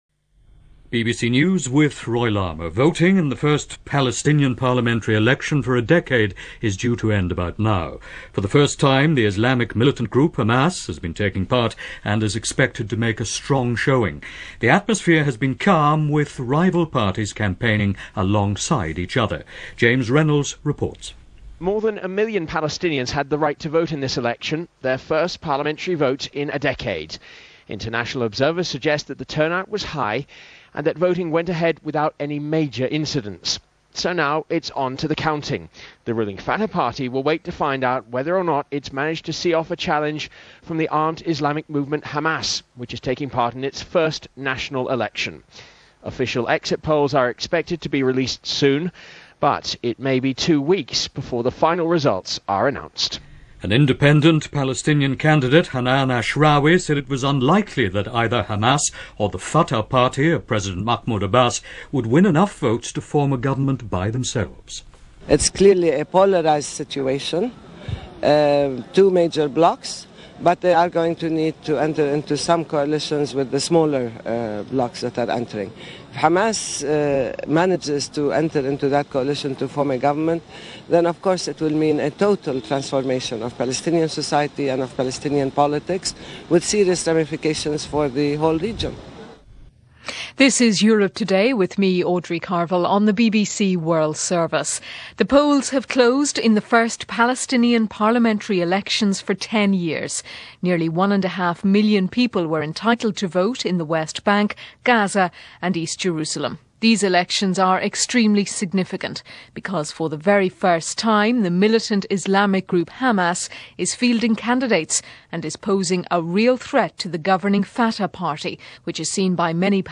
Hamas Wins An Election - January 25, 2006 - Past Daily After Hours Reference Room - news from the BBC World Service.
– BBC News – Palestinian Elections – CBS News – Condoleeza Rice Interview – Jan 25-27, 2006 –